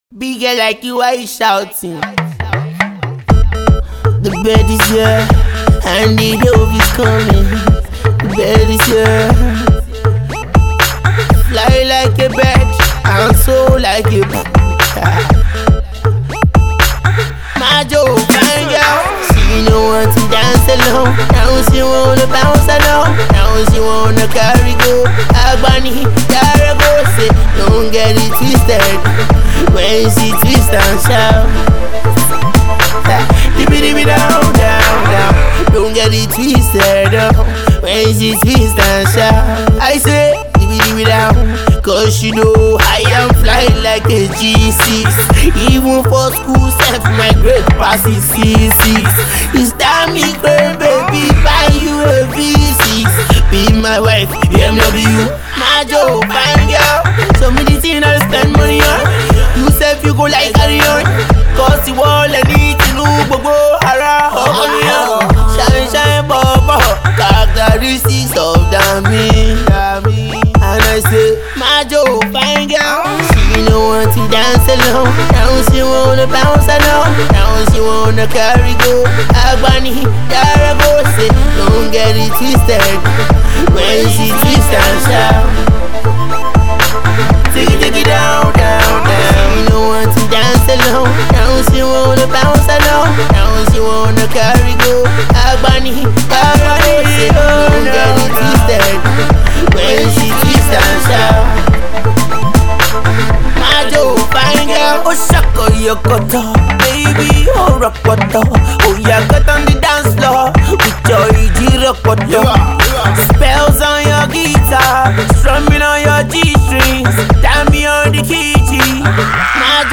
The fuji infused mid-tempo single